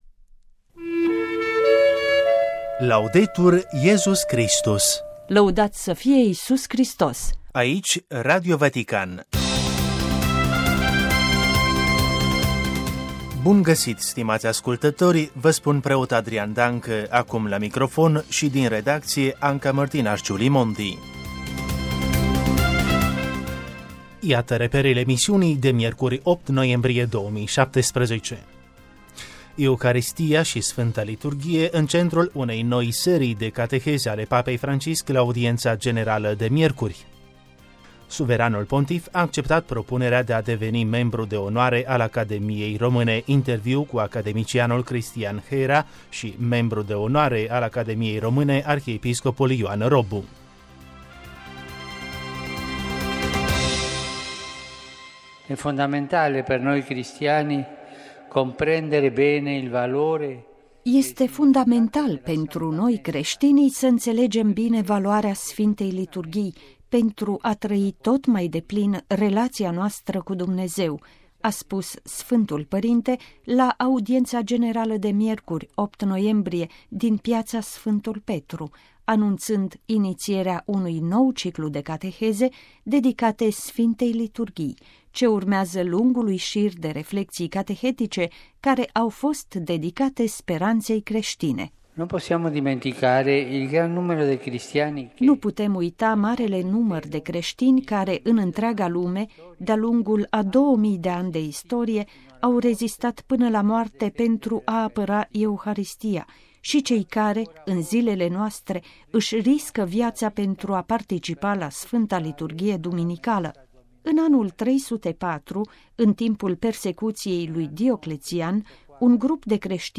Radio Vatican. Buletinul de știri al zilei: 8 noiembrie 2017
RV 8 nov 2017. Știrile zilei la Radio Vatican transmise în emisiunea radiofonică sau publicate pe pagina web: